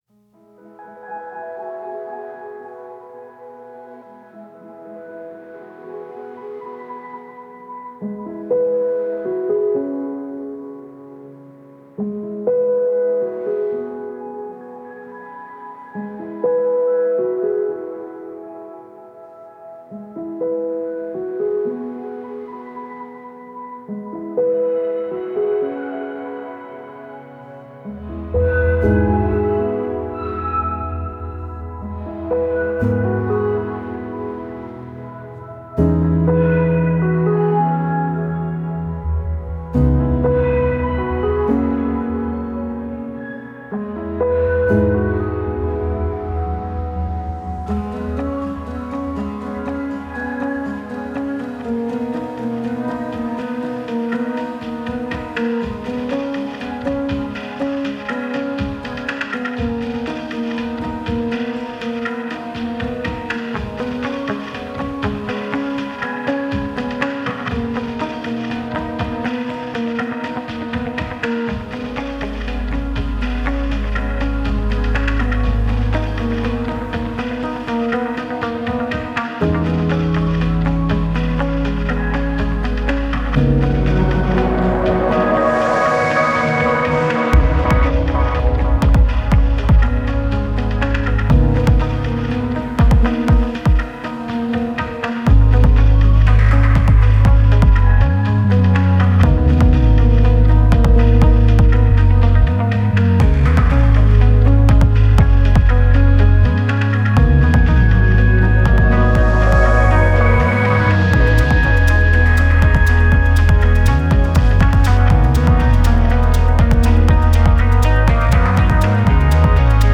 Granular synths, textural crackles and glitchy pulses.